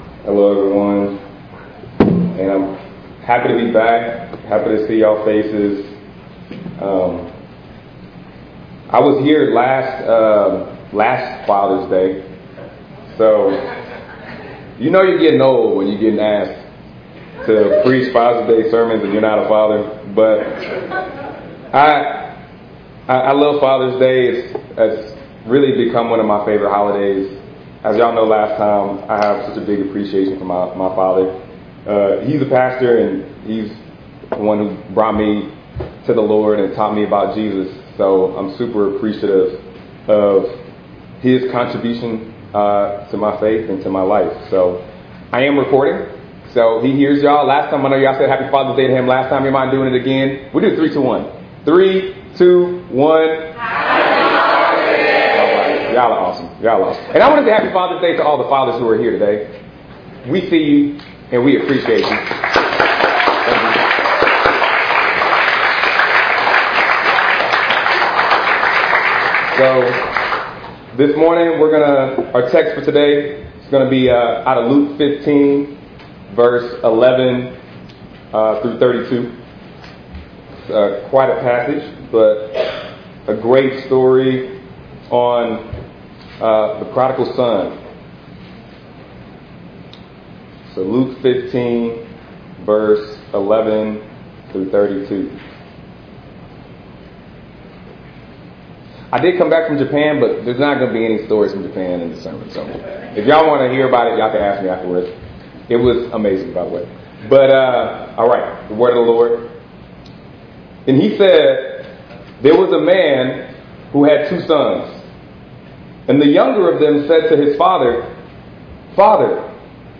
6_16_24_ENG_Sermon.mp3